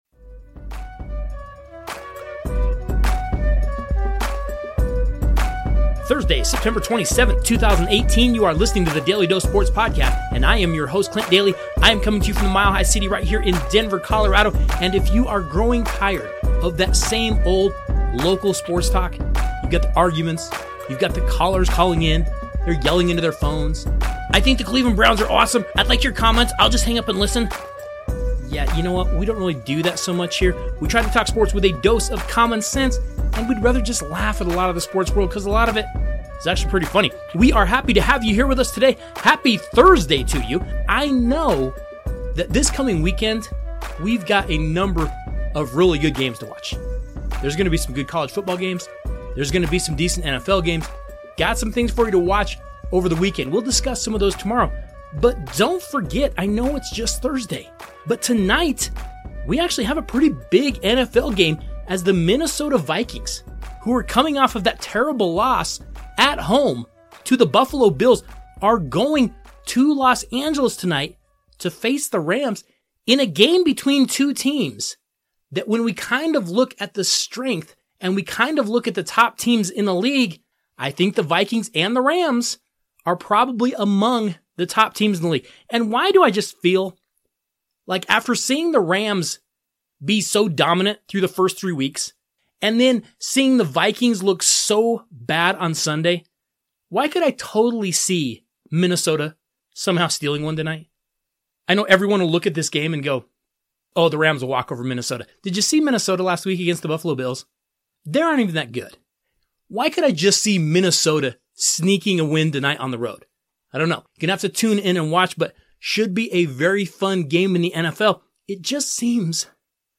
Finally, we continue our talk with a longtime caller, as we review the NFL season through the first three games, and he gives us his Super Bowl picks!